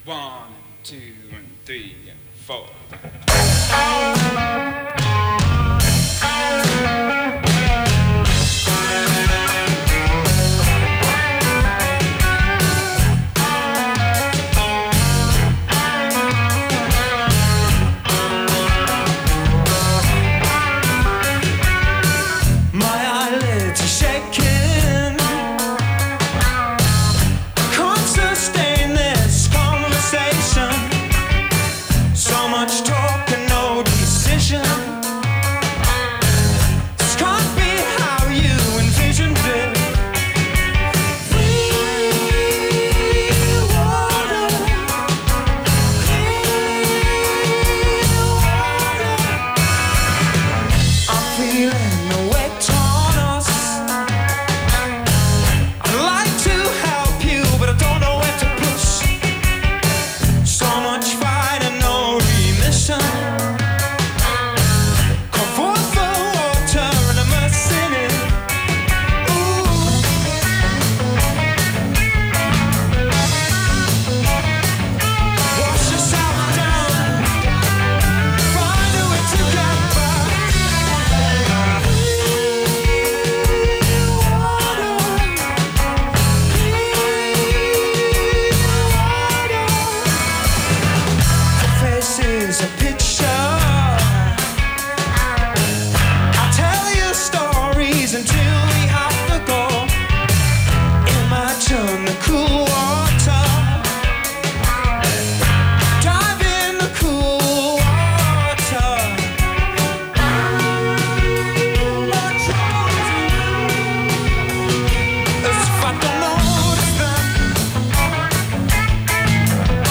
enregistrée le 22/02/2010  au Studio 105